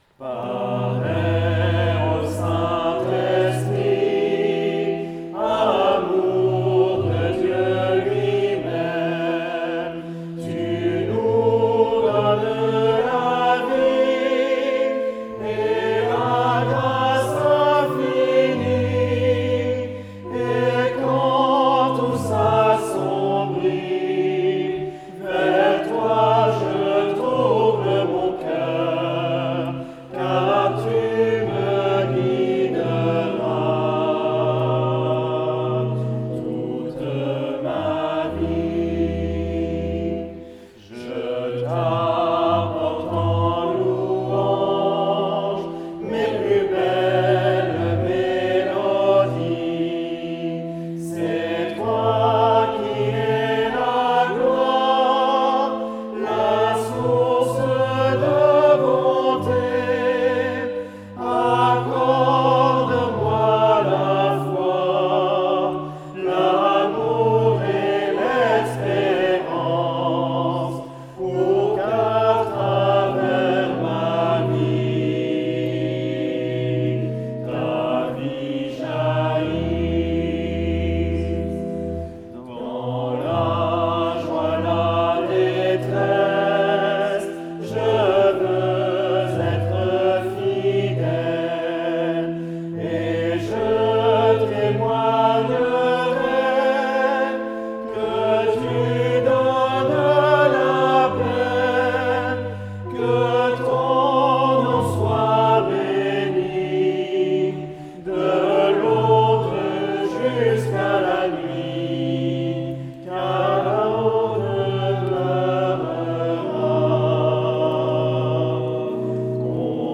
Parais ô Saint Esprit  vient d’un cantique allemand/arabe, sur un air populaire libanais.
Enregistrement amateur lors d’un culte dominical au temple de Roubaix :